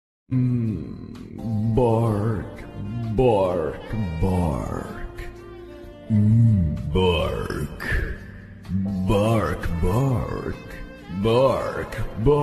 Play, download and share Barks for Makima original sound button!!!!
barks-for-makima.mp3